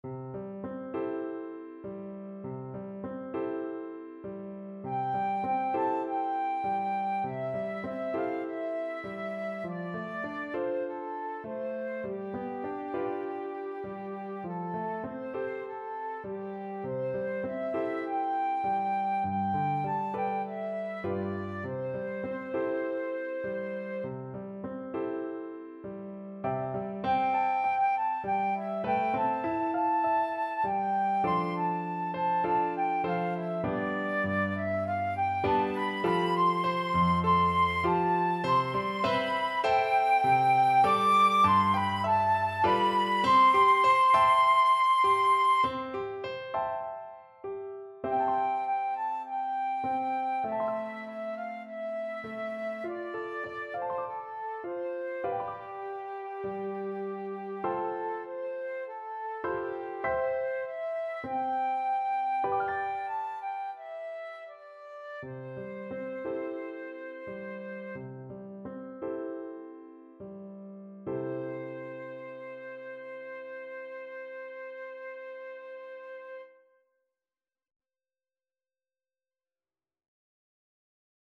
4/4 (View more 4/4 Music)
Gently =c.100
Flute  (View more Easy Flute Music)
Traditional (View more Traditional Flute Music)
world (View more world Flute Music)
South Korean